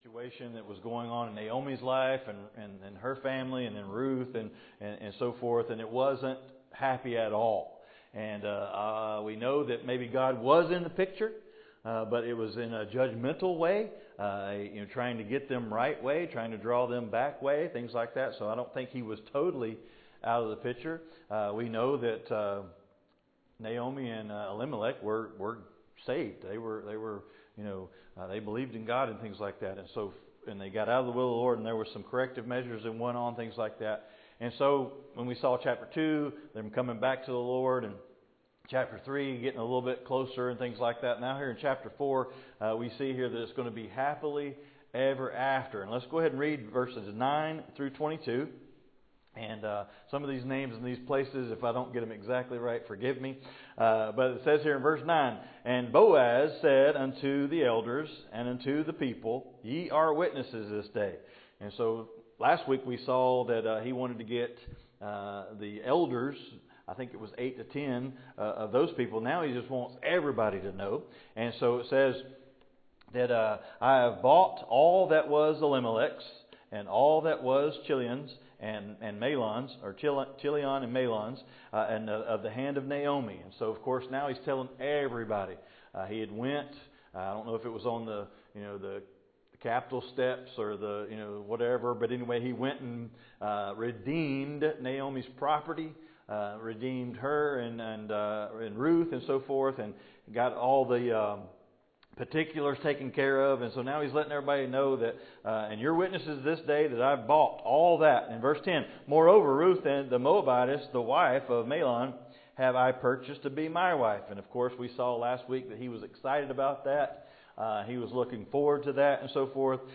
Passage: Ruth 4:9-22 Service Type: Wednesday Night